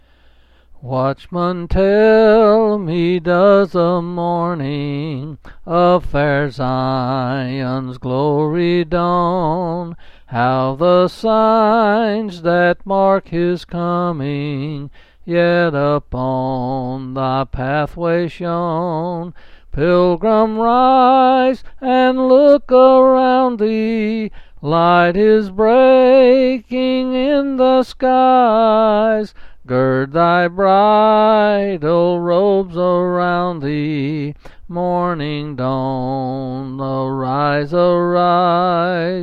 Quill Pin Selected Hymn
8s and 7s D.